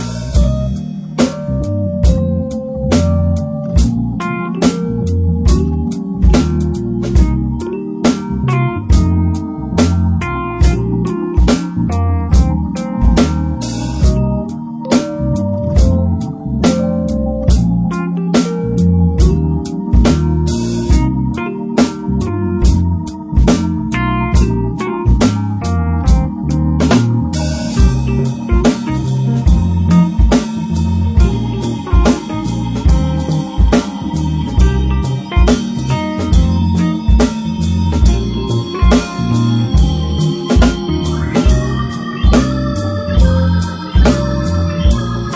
a slow jazz funk